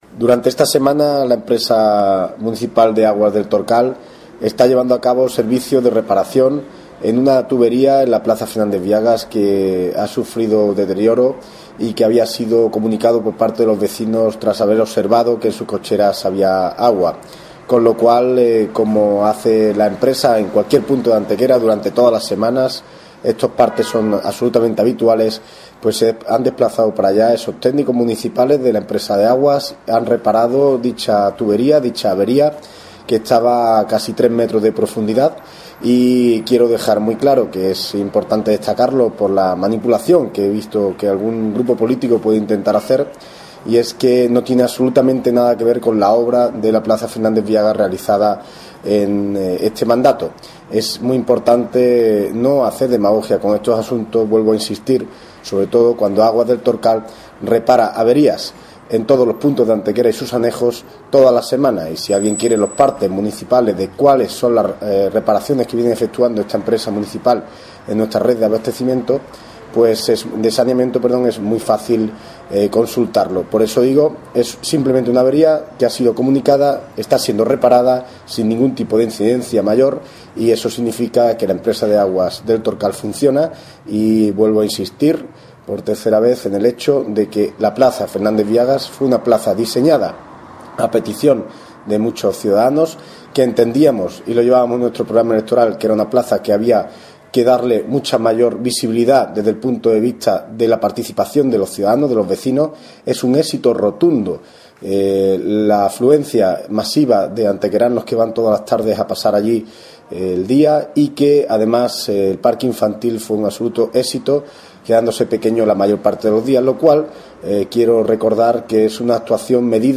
El servicio municipal de abastecimiento de aguas ya está completamente restablecido tras los trabajos que se vienen realizando esta semana y que, principalmente, han consistido en la sustitución de un tubo partido a 2,5 metros de profundidad además de otras labores posteriores que continúan a día de hoy. El teniente de alcalde delegado de Urbanismo y Desarrollo Industrial, José Ramón Carmona, informa de los trabajos de reparación de la rotura de una tubería en la plaza de Fernández Viagas que están siendo acometidos por operarios de la Empresa Municipal de Abastecimiento de Aguas de Antequera, “Aguas del Torcal”.
Cortes de voz J.R. Carmona 885.71 kb Formato: mp3